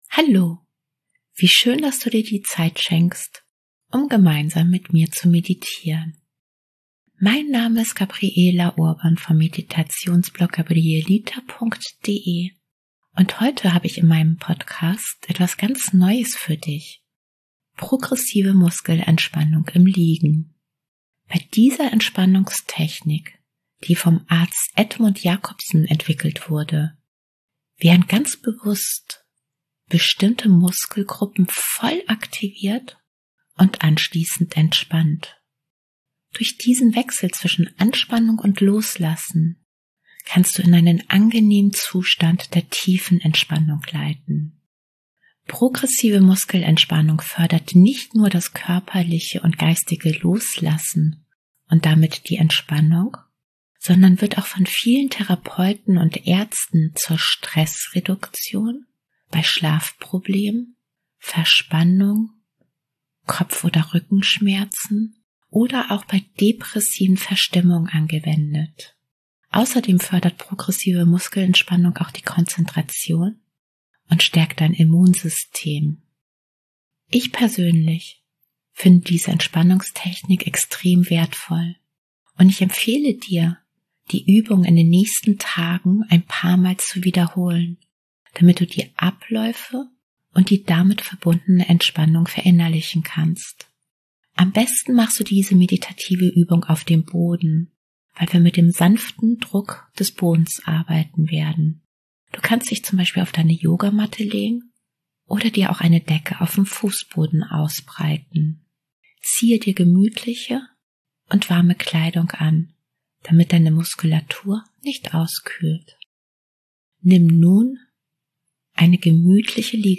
#075: Meditation Progressive Muskelentspannung im Liegen